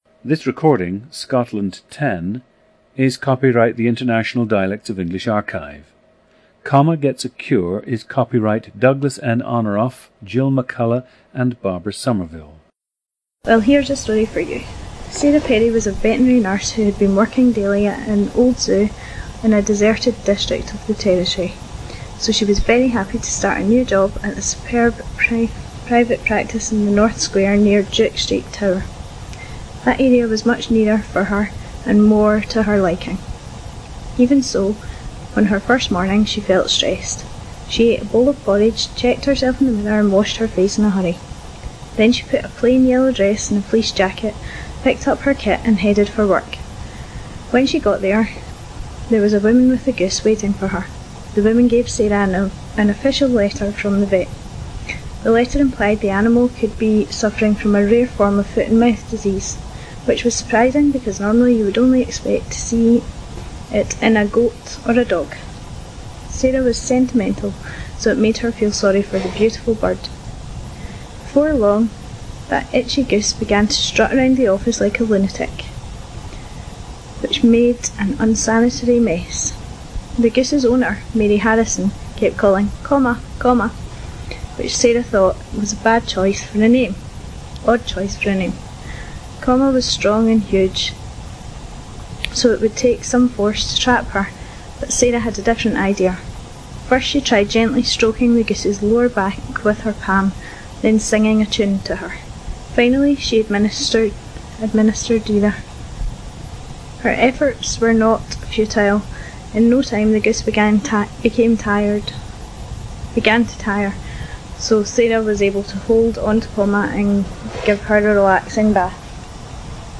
GENDER: female
This is an excellent example of a young white female from Glasgow. Notice the rising intonation at the ends of some sentences and the quality of the vowel in “you,” “food,” etc. Note the very rounded quality to the vowel in “home” and “goat.” Note the use of the tapped and trilled “r.”
The recordings average four minutes in length and feature both the reading of one of two standard passages, and some unscripted speech.